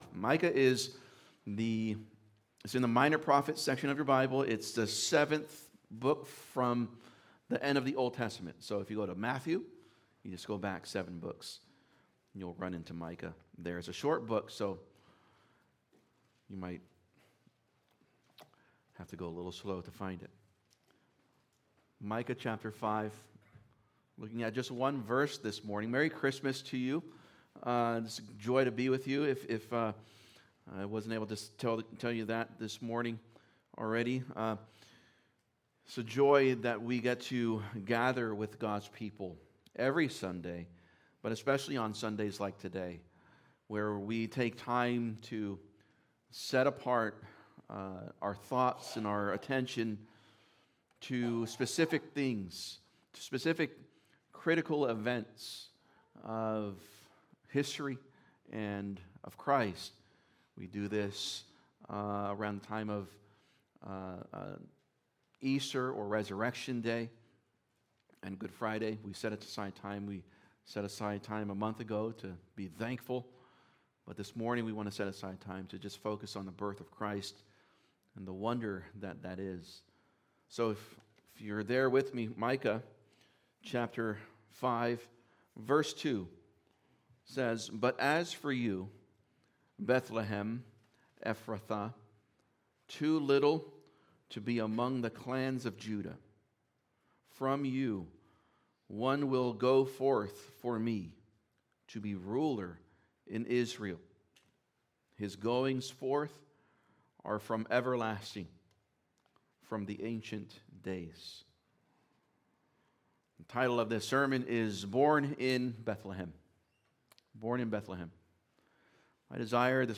Christmas Service | Born in Bethlehem | Redeemer Bible Church